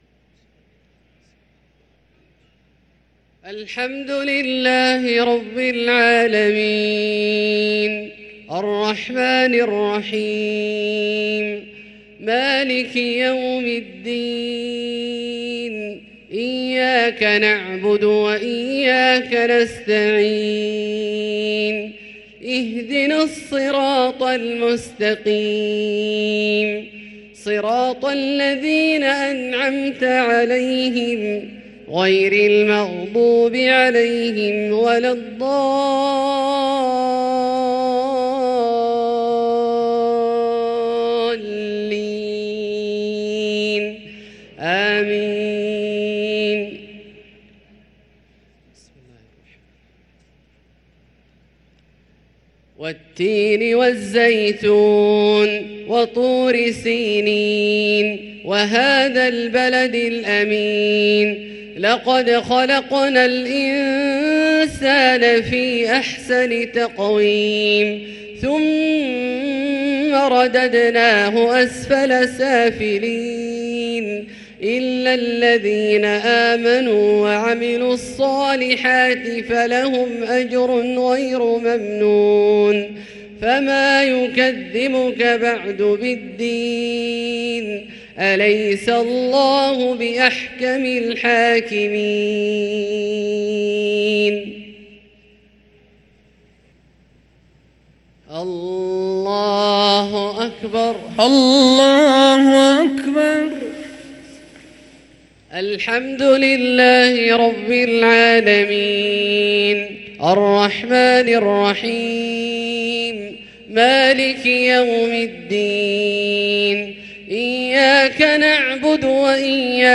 صلاة العشاء للقارئ عبدالله الجهني 3 رمضان 1444 هـ